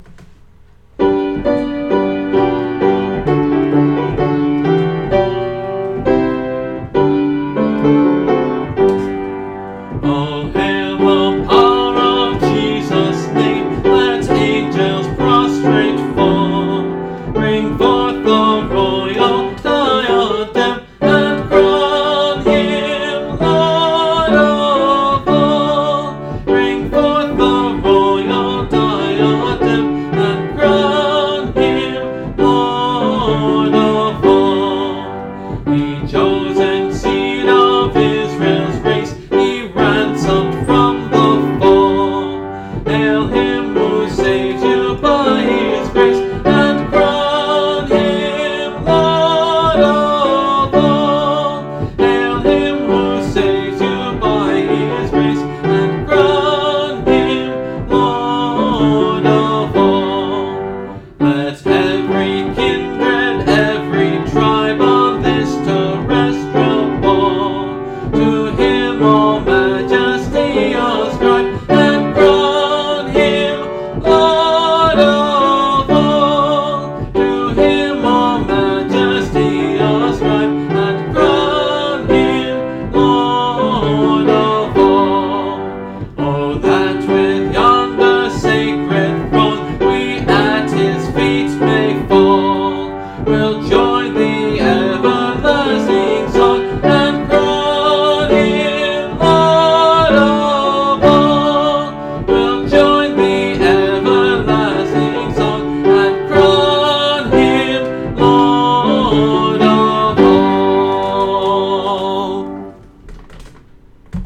(Part of a series singing through the hymnbook I grew up with: Great Hymns of the Faith)
Some of the audio files are going to be better quality than others. Depends on my skill to play and sing at the same time that day, and how well I know the hymn.